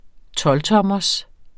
Udtale [ ˈtʌlˌtʌmʌs ]